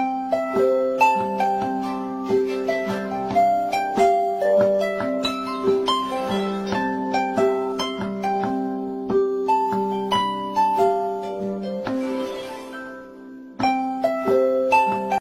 Catégorie l’alarme/reveil